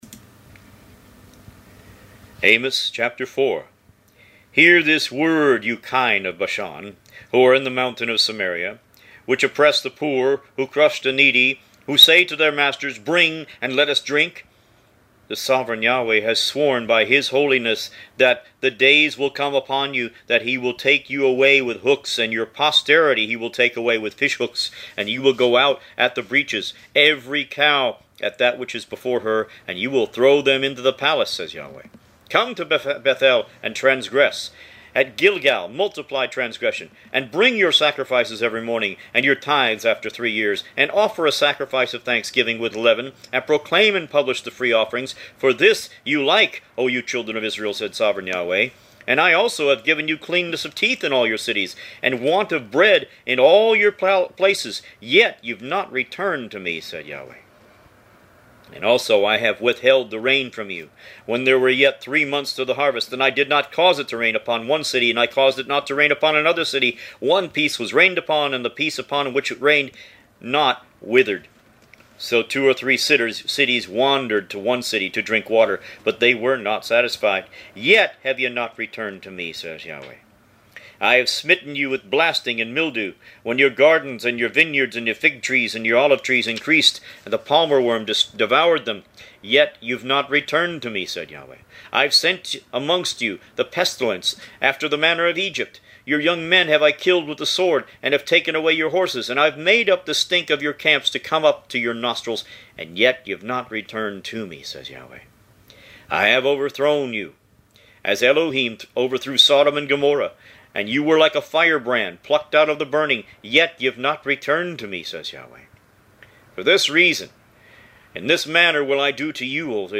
Root > BOOKS > Biblical (Books) > Audio Bibles > Tanakh - Jewish Bible - Audiobook > 30 Amos